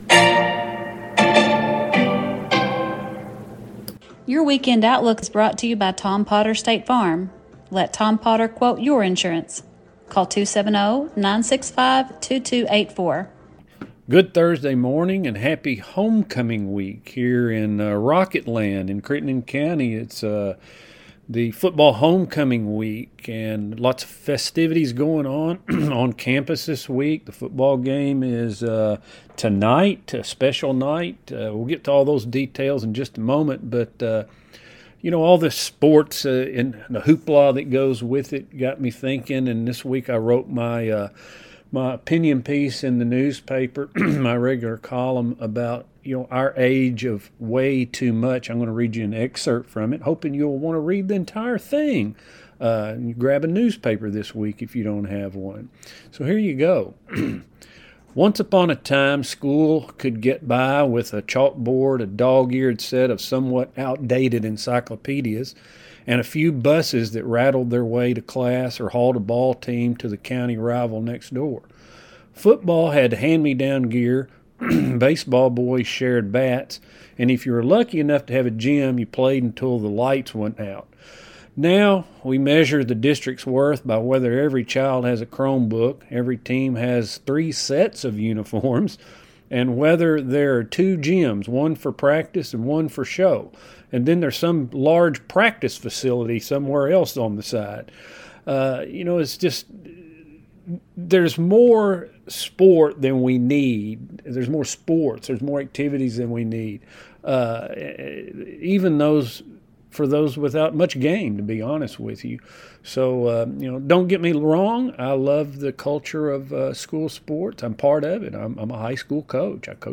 News | Sports | Interview